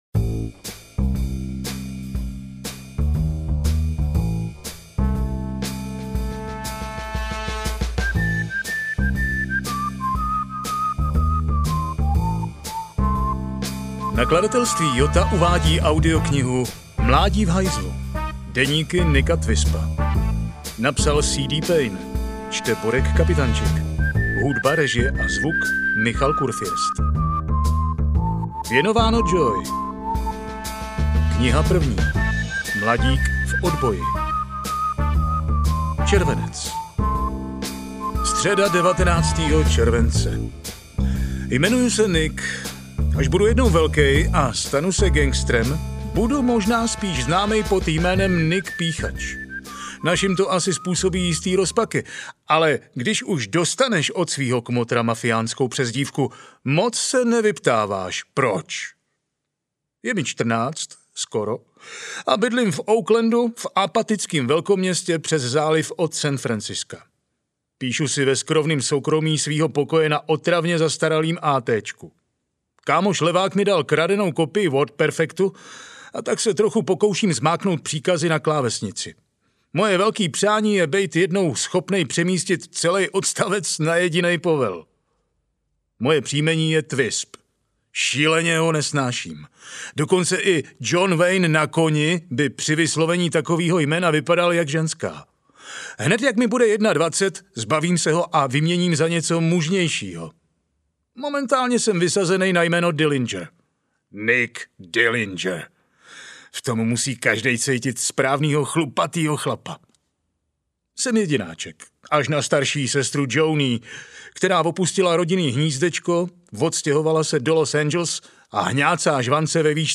Mládí v hajzlu 1 audiokniha
Ukázka z knihy